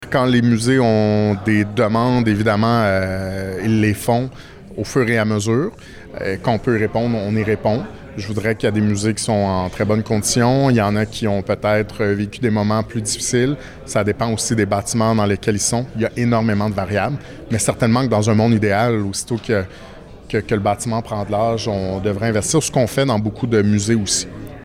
Lors de la conférence de presse de mardi, le ministre de la Culture et des Communications, Mathieu Lacombe était présent.